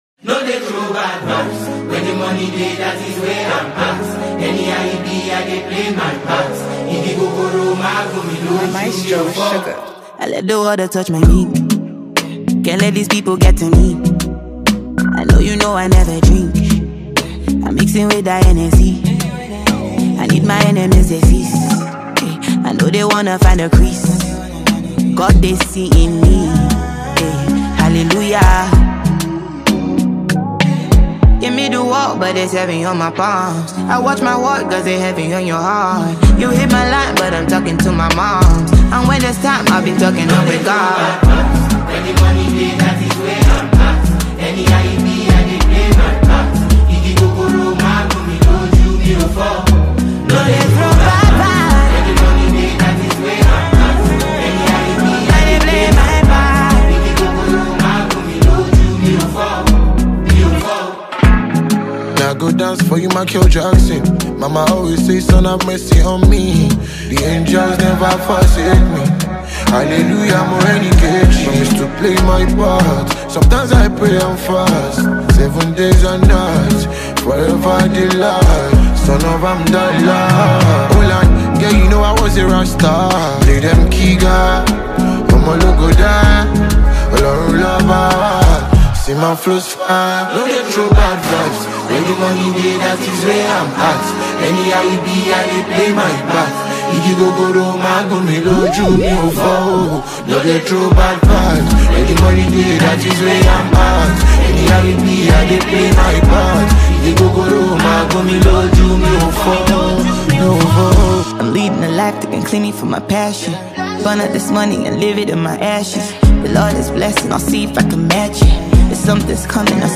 Award-winning Nigerian female singer